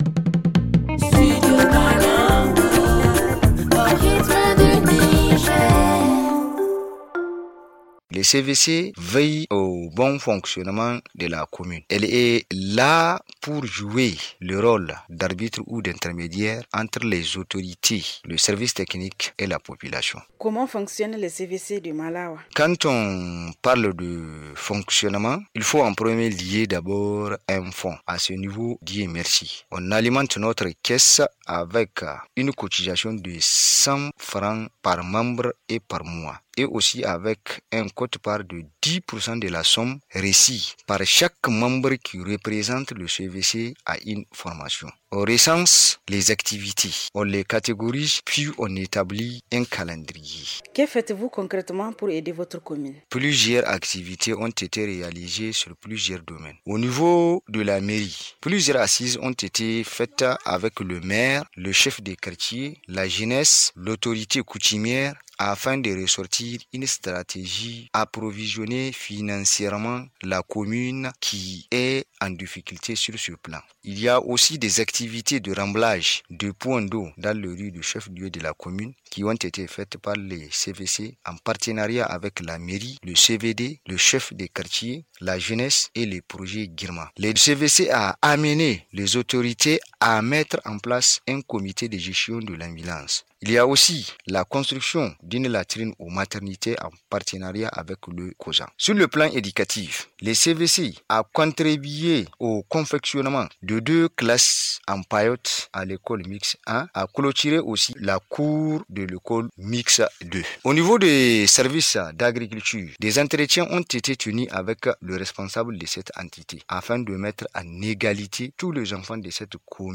Le magazine en français